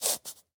Minecraft Version Minecraft Version snapshot Latest Release | Latest Snapshot snapshot / assets / minecraft / sounds / mob / fox / sniff3.ogg Compare With Compare With Latest Release | Latest Snapshot
sniff3.ogg